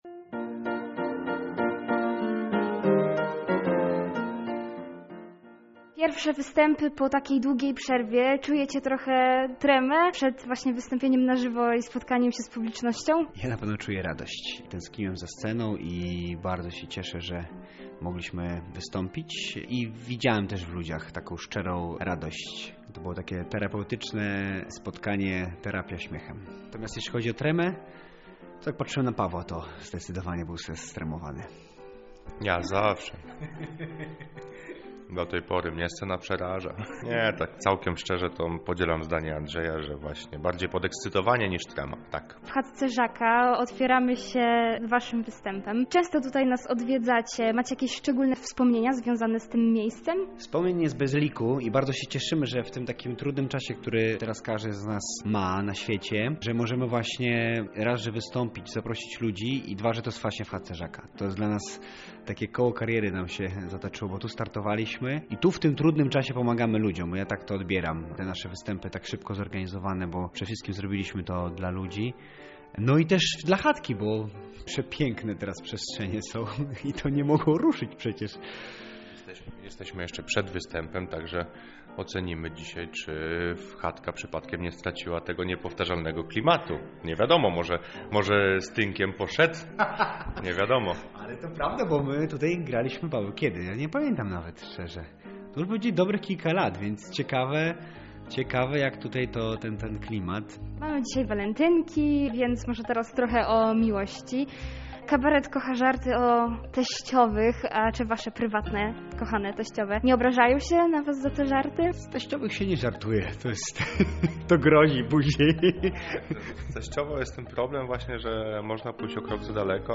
Danie Główne: Wywiad z Kabaretem Smile